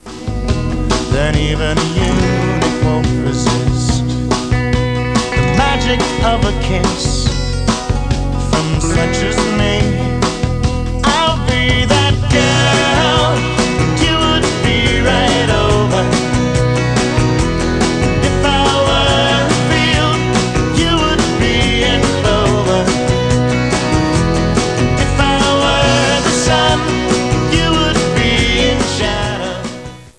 guitarist
drummer